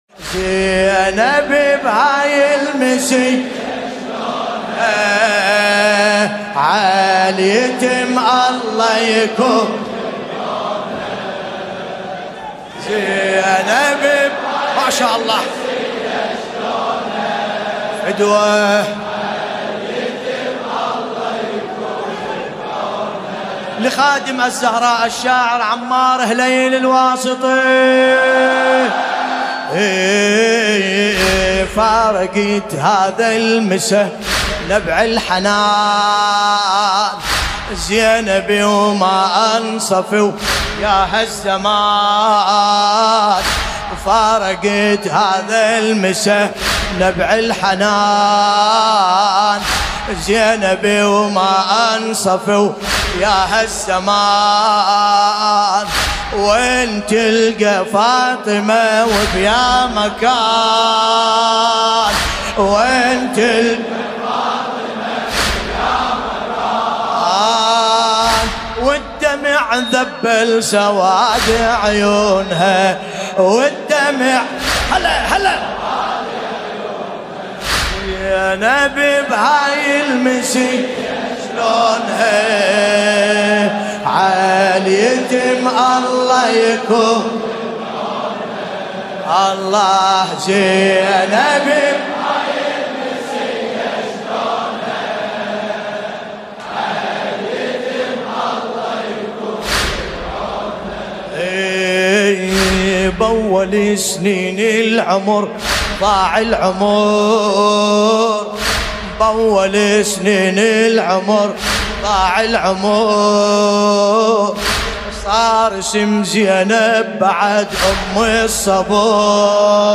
المناسبة : الليالي الفاطمية الثانية